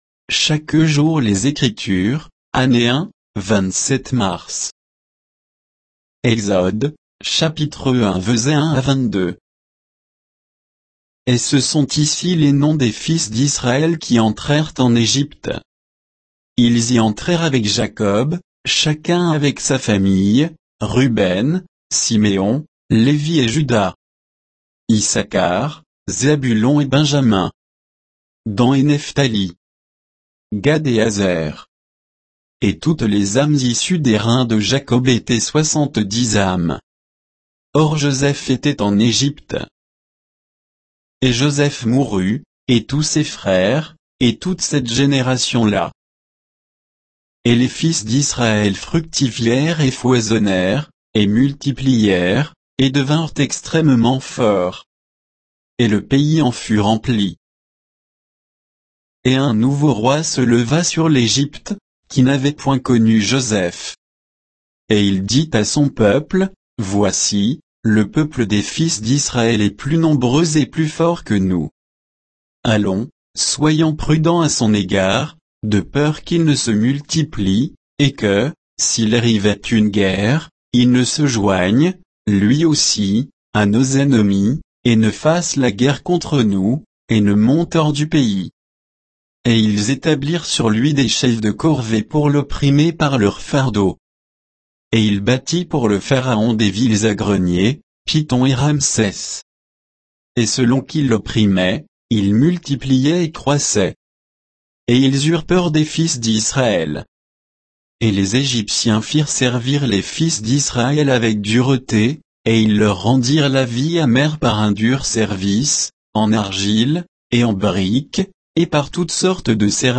Méditation quoditienne de Chaque jour les Écritures sur Exode 1